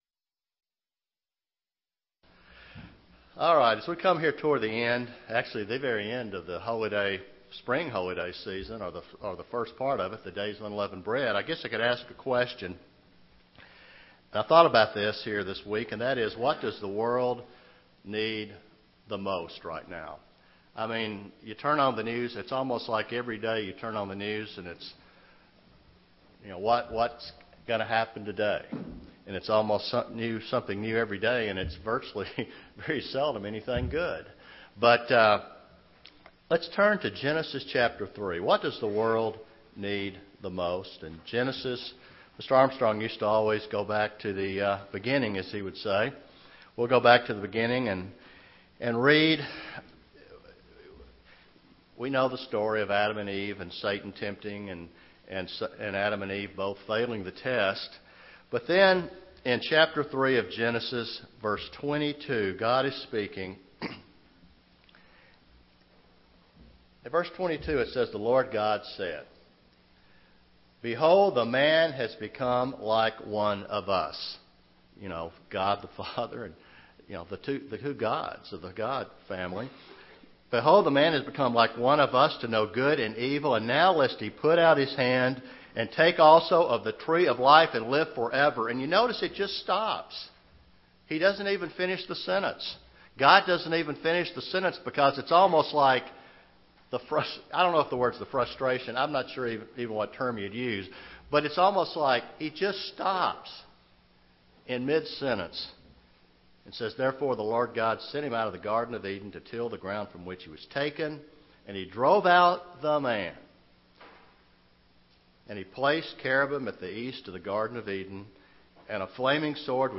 Given the times in which we live, what does the world need most right now? This message was given on the Last Day of Unleavened Bread.
Given in Chattanooga, TN
UCG Sermon Studying the bible?